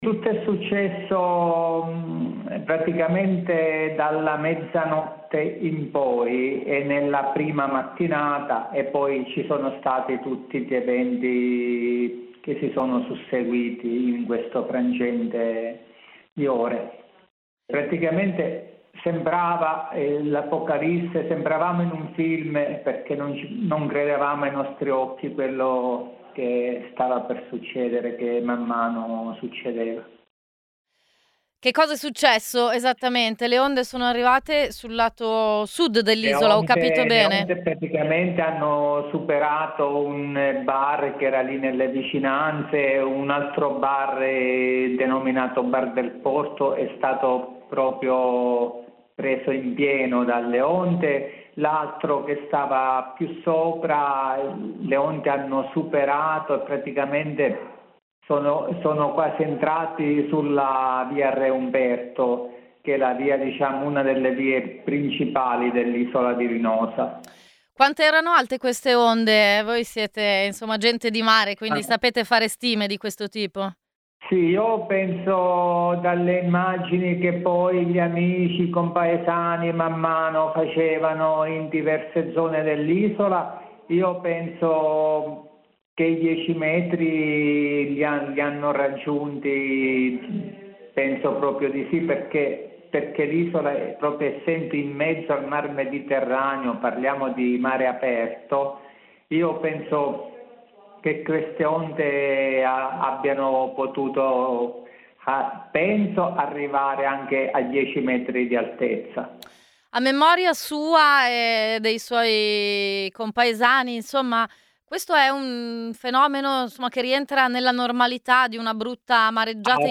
intervistato